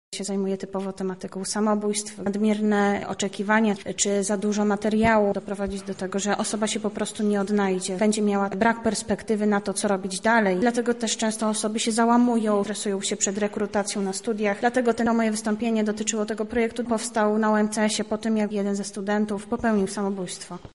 Nad tym pytaniem zastanowili się uczestnicy konferencji naukowej na Wydziale Politologii UMCS.